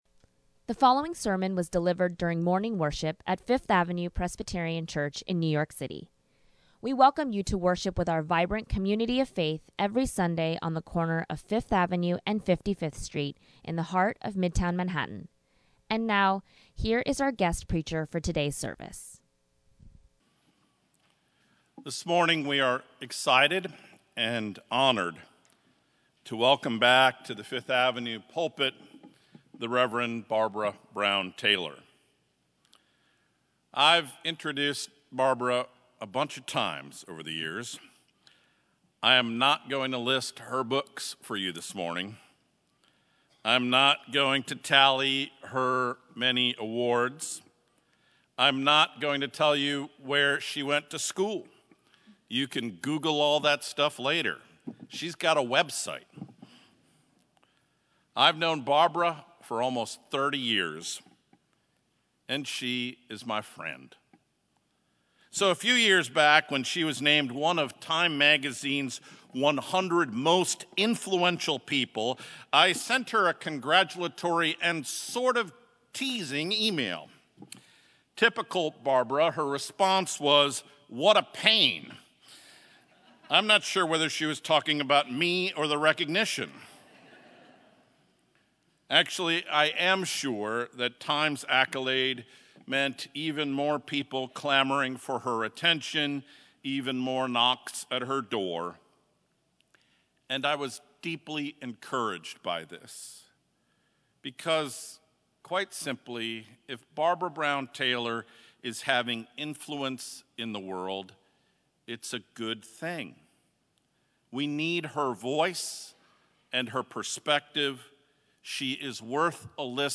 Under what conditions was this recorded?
Twenty-Fifth Sunday after Pentecost